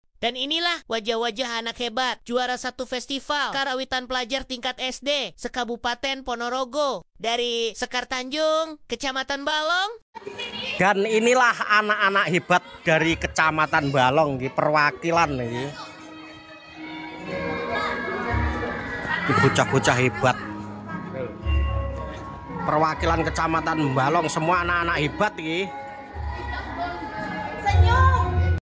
JUARA 1 FESTIVAL KARAWITAN PELAJAR